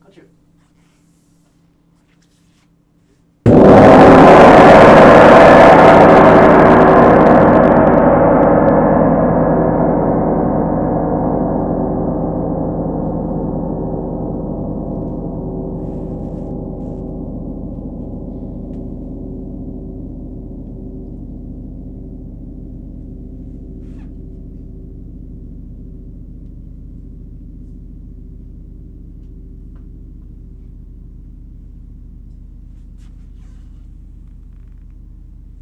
Grand_forte.wav